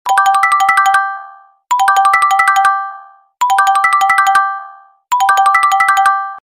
iphone-crack_24921.mp3